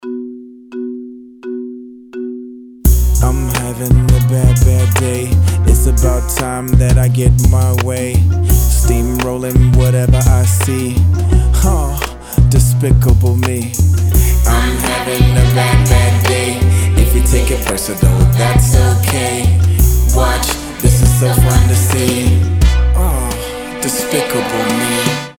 • Качество: 320, Stereo
Хип-хоп
саундтреки
спокойные